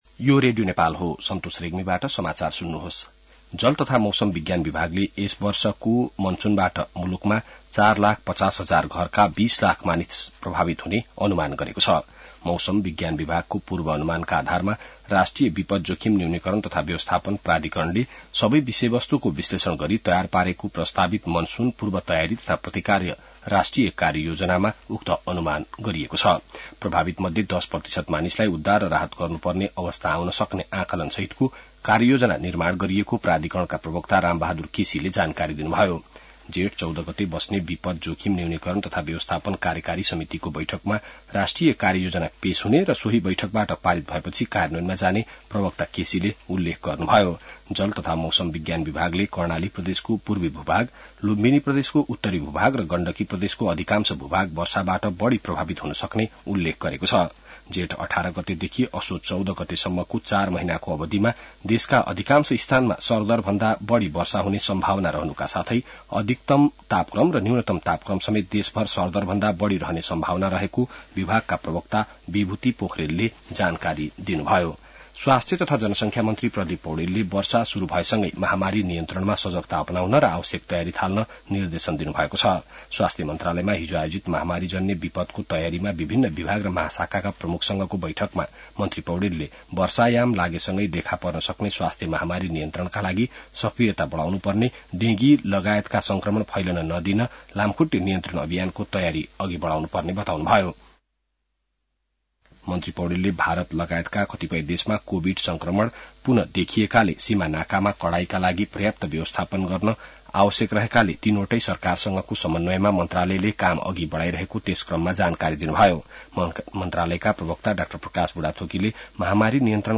बिहान ६ बजेको नेपाली समाचार : १२ जेठ , २०८२